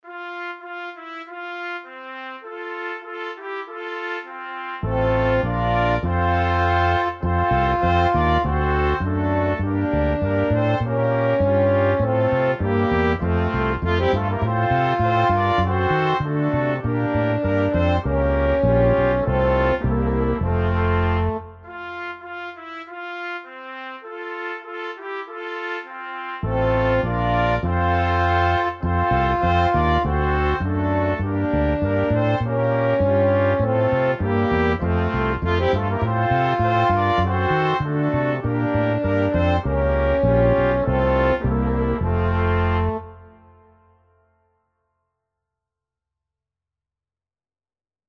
Dychové kvinteto Značky: Inštrumentalne
Vianočné koledy a piesne Zdieľajte na